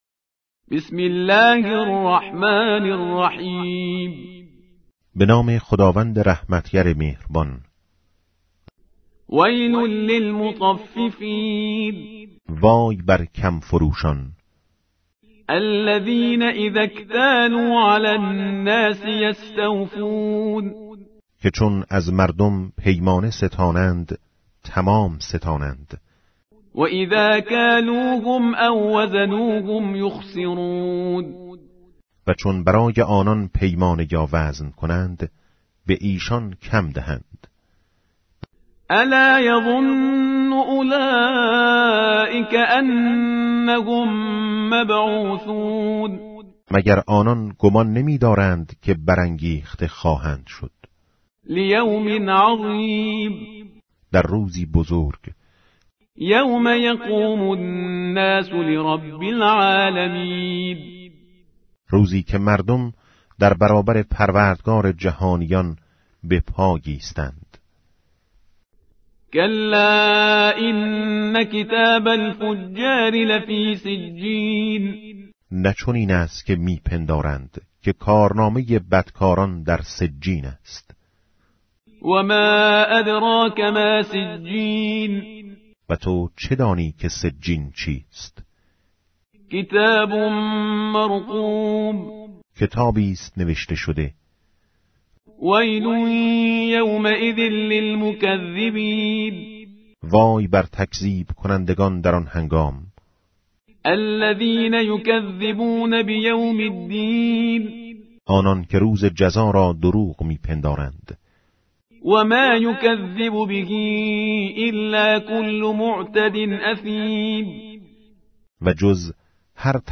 ترجمه و ترتیل قرآن کریم